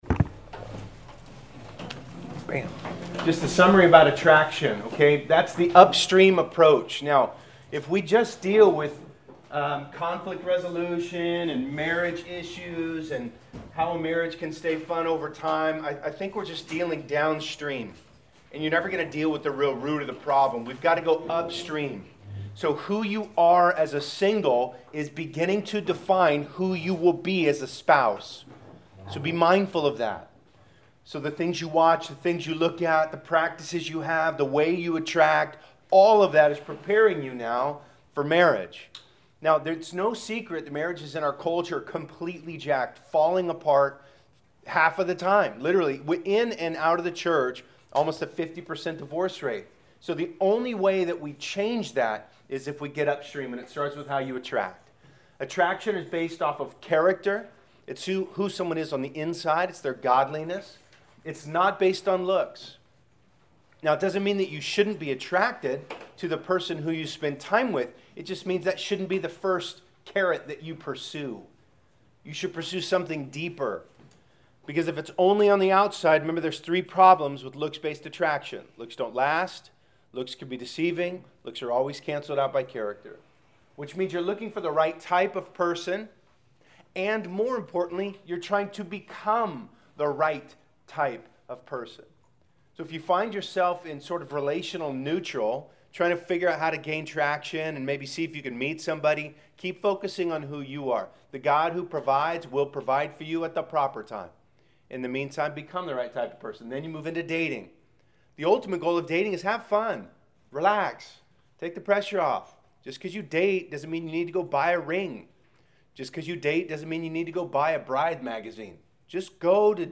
Class Session Audio February 03